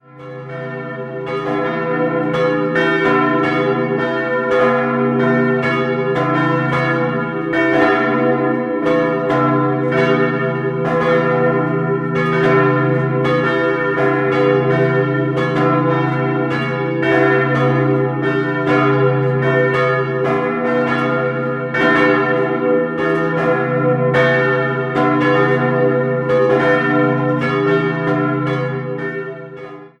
3-stimmiges Geläute: h°-e'-gis' Die große und die kleine Glocke wurden im Jahr 1918 von der Gießerei Schilling&Lattermann in Apolda/Morgenröthe-Rautenkranz gegossen. Eine dritte Eisenglocke wurde 1998 von Lauchhammer ersetzt. Das Geläut befindet sich übrigens nicht im Kirchturm, sondern in einem hölzernen Glockenhaus auf dem Friedhof.